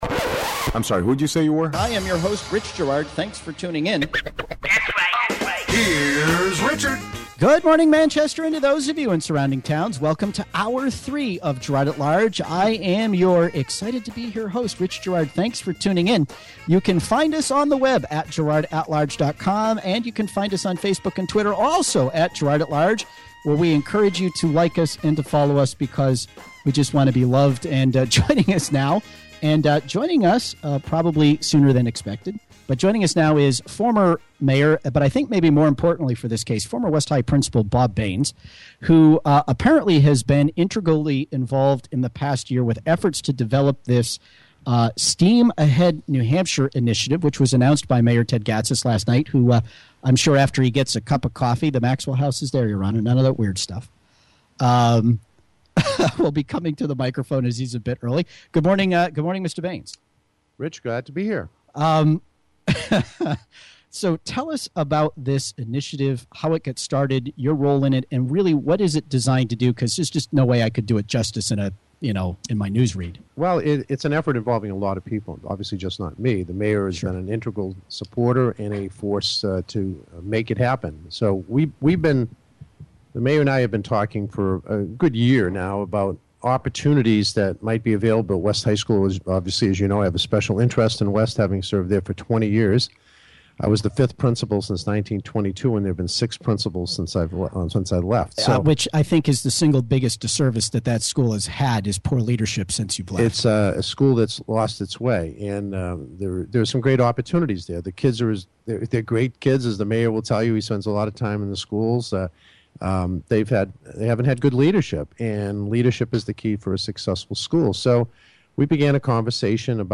(Hour 3a, b, c) Former Manchester Mayor and West High School Principal Bob Baines joined current Mayor Ted Gatsas to announce the details of an exciting educational initiative being planned at the underutilized school.